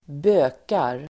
Ladda ner uttalet
Uttal: [²b'ö:kar]